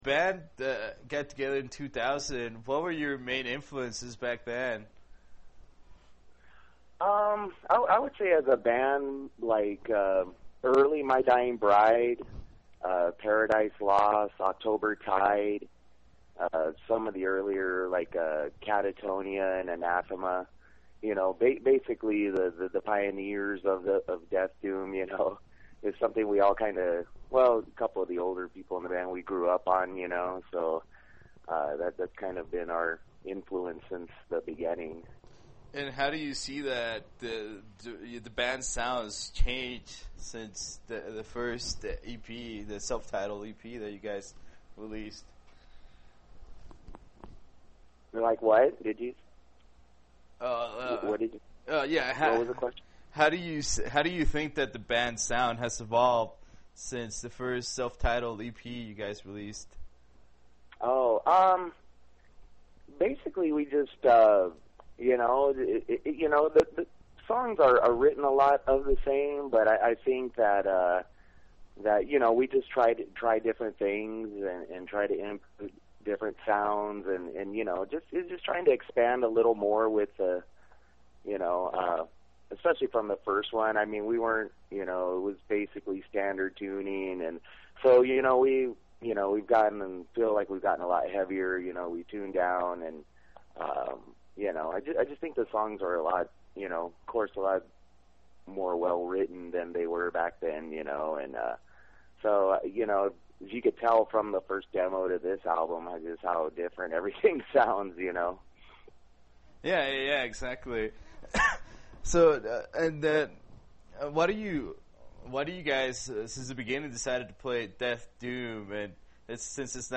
In our interview we talk about the long journey the band has had to endure in order to keep the Death/Doom flame alive in the USA. We also discus the band’s new record deal, future plans, among other things. Select click HERE to listen to this interview or select Save As, and take it with you.